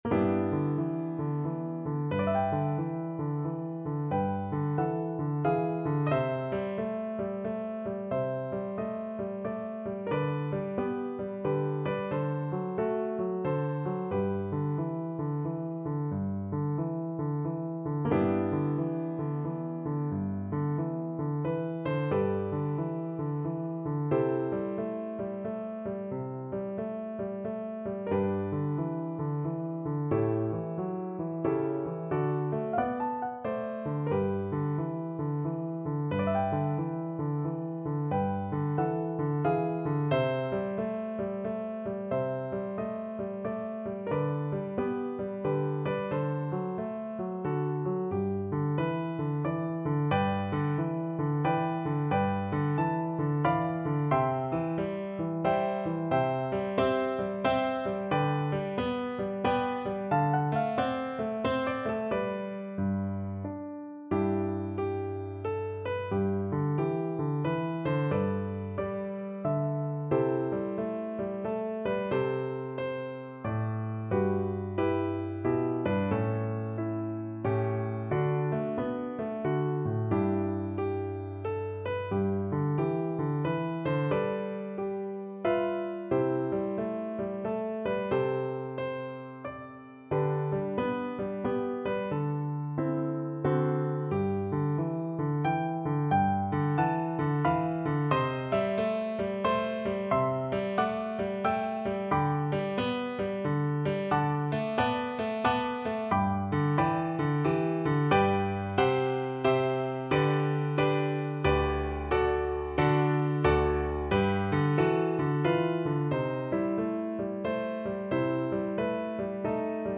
3/4 (View more 3/4 Music)
Relaxed Swing =c.90